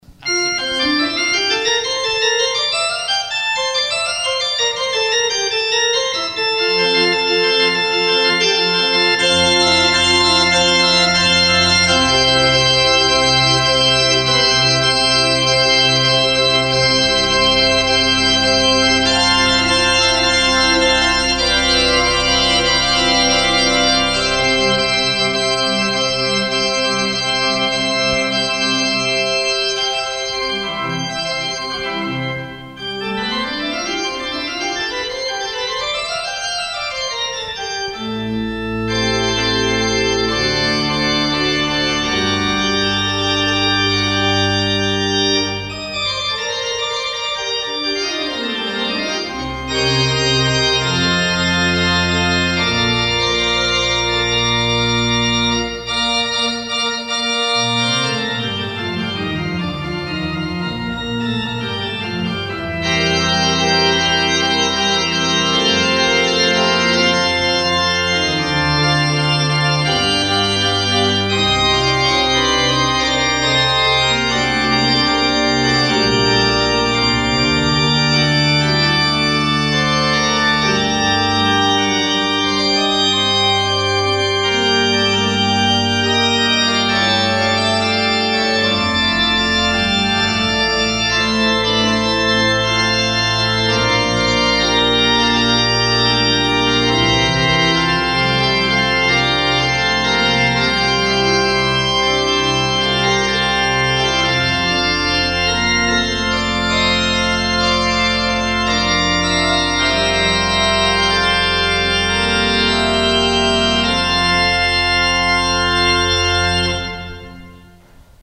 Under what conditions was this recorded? in the Calvin Auditorium, Geneva.